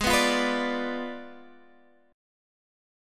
Abdim7 chord